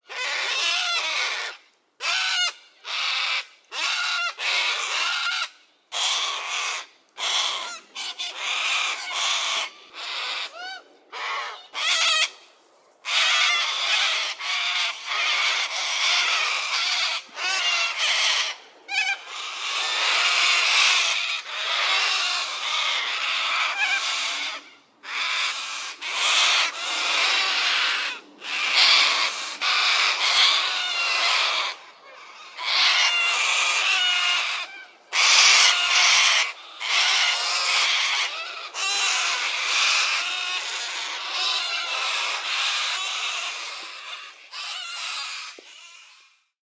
科里亚斯 " 科里亚斯的尖叫声
描述：澳大利亚科雷拉的鸟儿匆匆而过。
标签： 澳大利亚 鸟叫声 尖叫 科雷拉 现场录音 澳大利亚
声道立体声